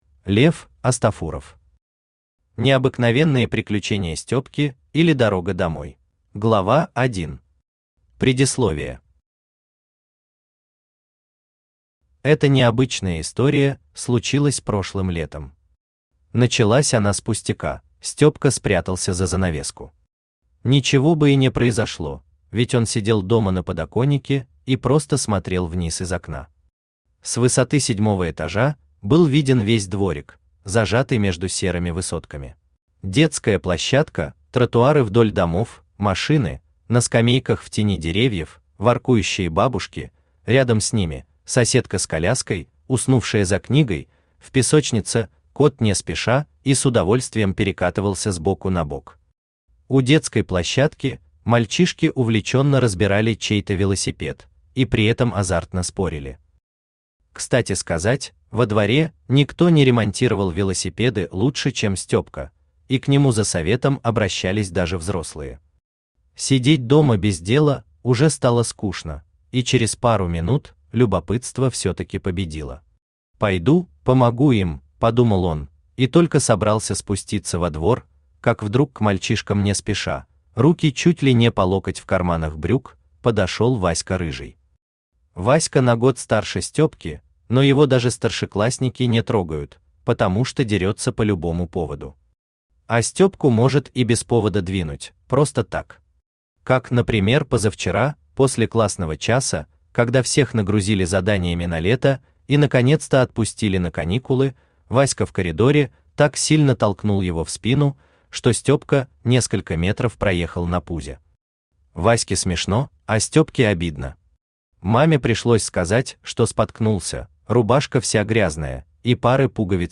Аудиокнига Необыкновенные приключения Стёпки, или Дорога домой | Библиотека аудиокниг
Aудиокнига Необыкновенные приключения Стёпки, или Дорога домой Автор Лев Астафуров Читает аудиокнигу Авточтец ЛитРес.